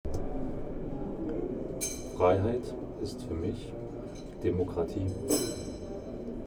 Standort der Erzählbox:
Stendal 89/90 @ Stendal